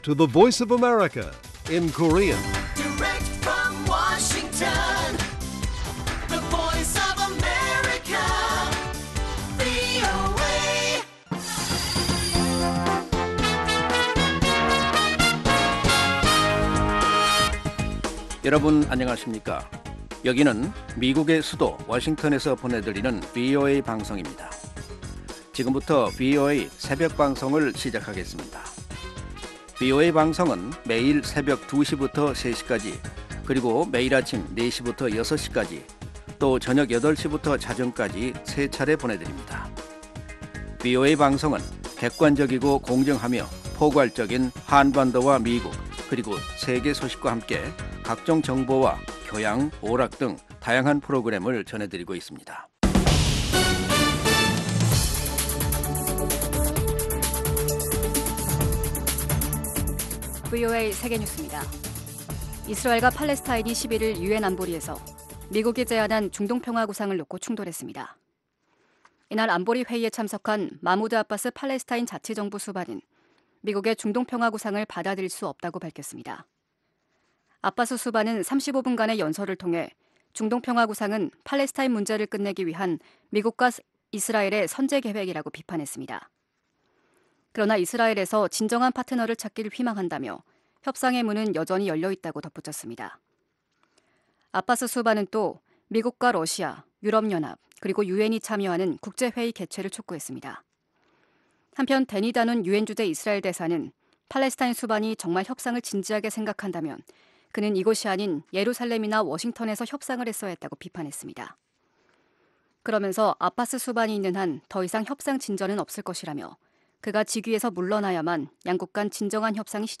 VOA 한국어 '출발 뉴스 쇼', 2020년 2월 13일 방송입니다. 미국 백악관 국가 안보보좌관은 미-북 추가 정상회담이 적절한지 여부는 지켜봐야 할 것이나, 트럼프 대통령은 늘 정상회담에 나설 의향이 있다고 말했습니다. 트럼프 대통령이 11월 미국 대통령 선거 전에 김정은 위원장을 만나길 원치 않는다는 미국 언론의 보도는 사실이 아니라고, 청와대가 밝혔습니다.